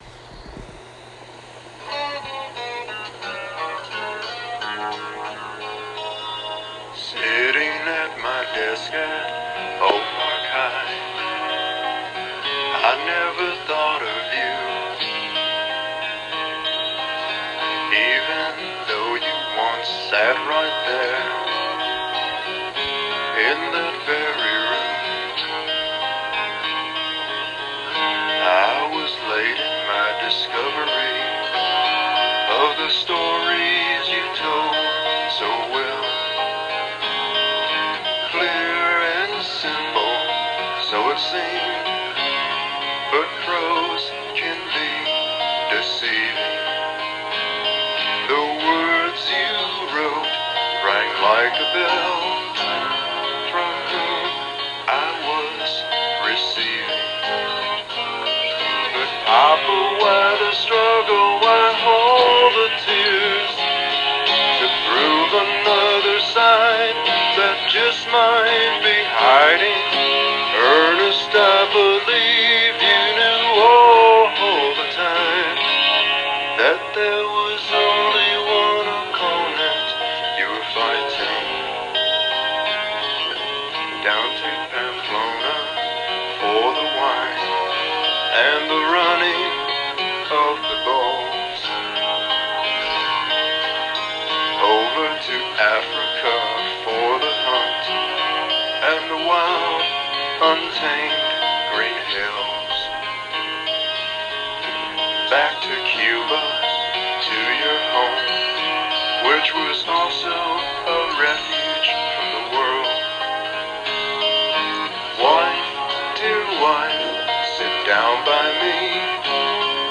reflective biographical sermon